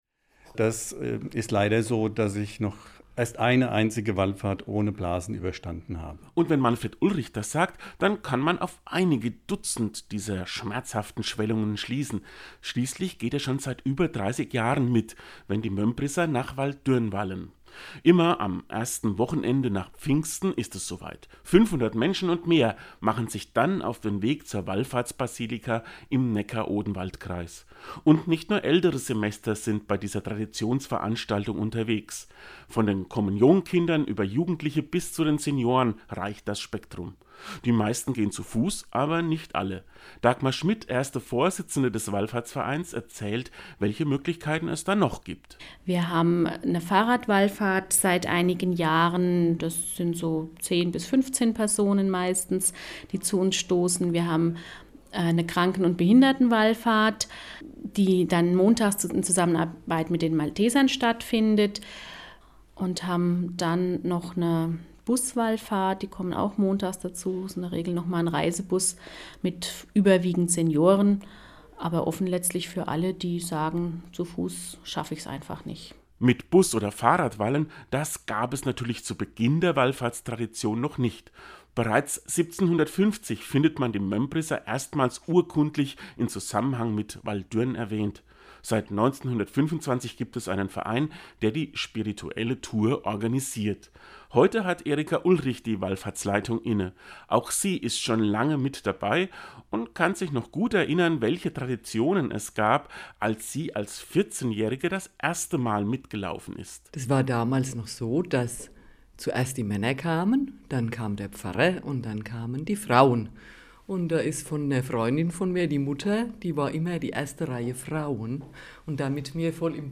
Seinen Radiobeitrag finden Sie unten zum Downloaden!